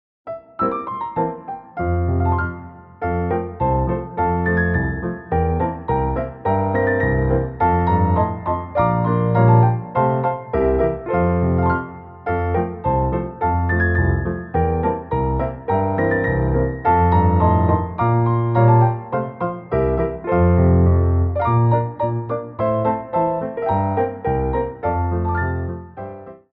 Coda
2/4 (16x8)